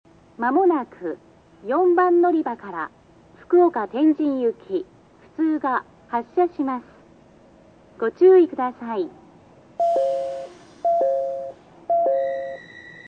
●スピーカー：ソノコラム・小
●音質：D
４番のりば 発車放送+サイン音　普通・福岡天神　（64KB/13秒）
全ホーム同じ声で統一されていますが、ちょっとイントネーションがおかしいような......。